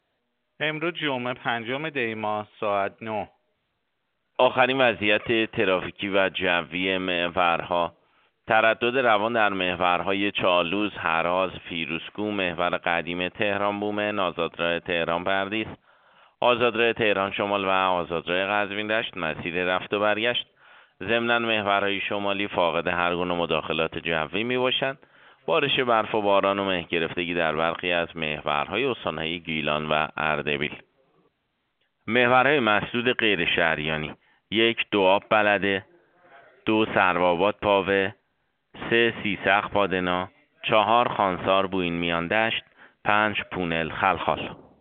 گزارش رادیو اینترنتی از آخرین وضعیت ترافیکی جاده‌ها ساعت ۹ پنجم دی؛